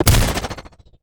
Sci-Fi Effects
weapon_railgun_003.wav